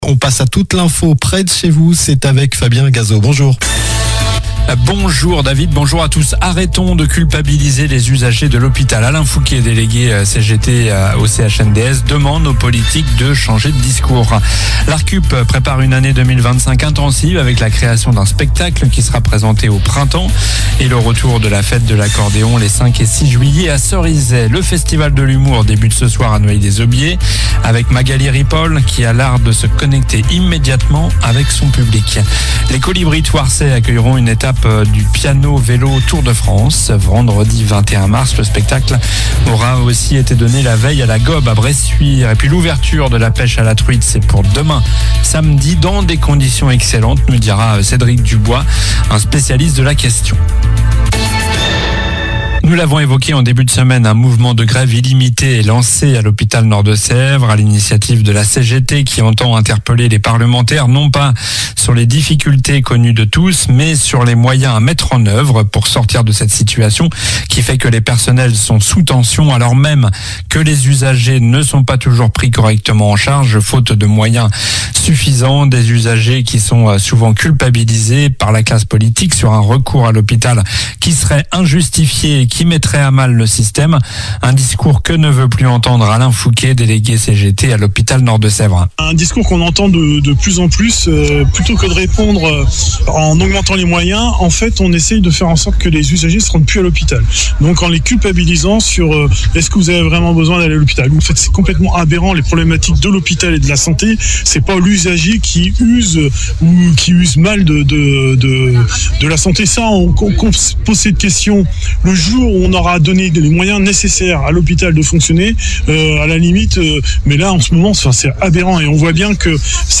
Journal du vendredi 07 mars (midi)